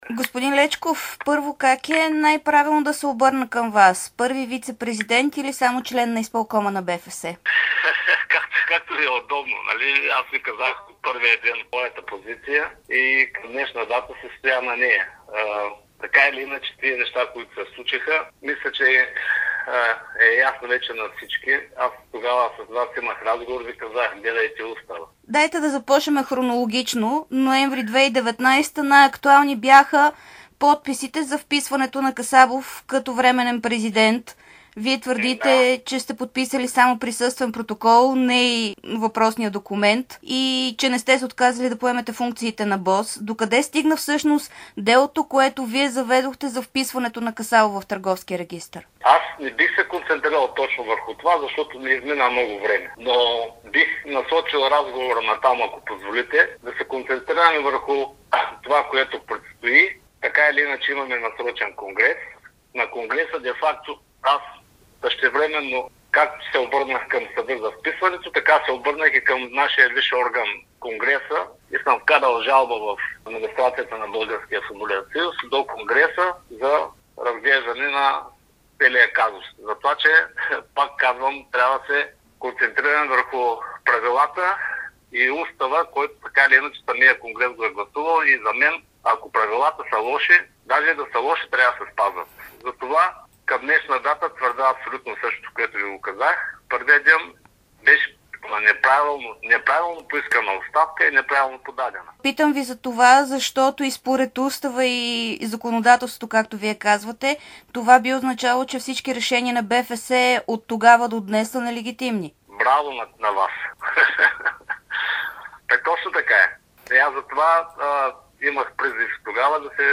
Йордан Лечков даде обширно интервю специално за dsport и Дарик радио.